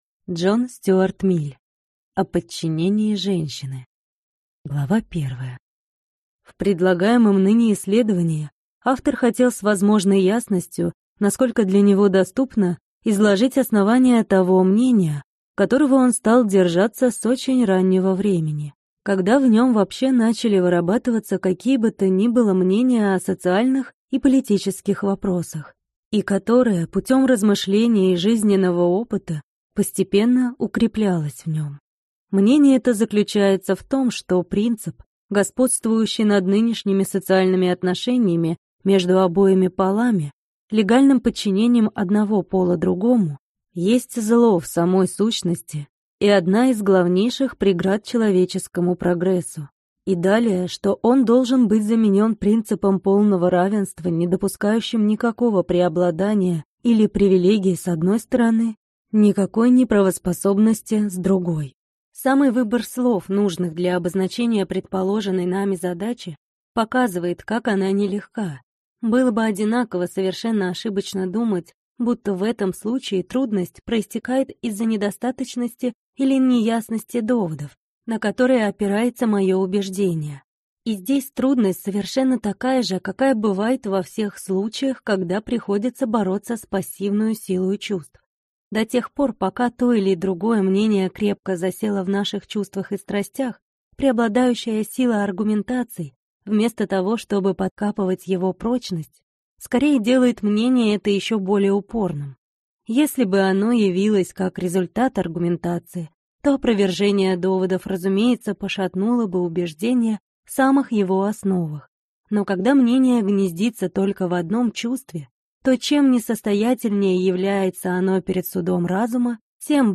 Аудиокнига О подчинении женщины | Библиотека аудиокниг